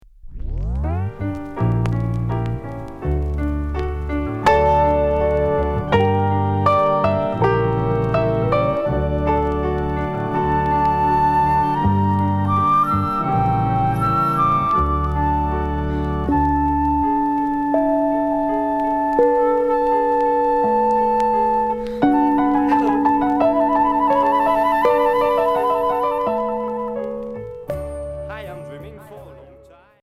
Folk progressif Unique 45t retour à l'accueil